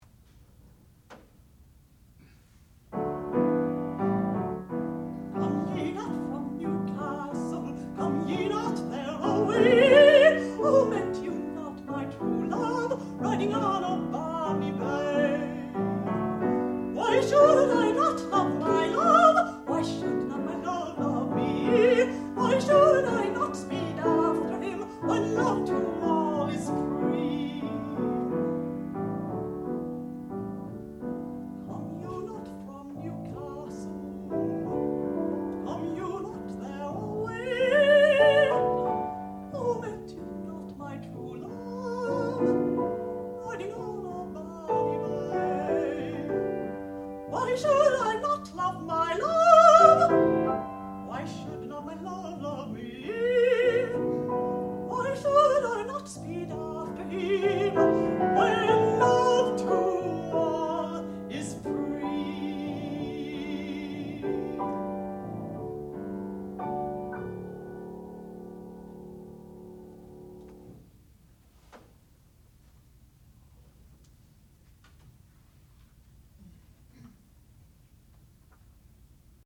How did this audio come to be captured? Master's Degree Recital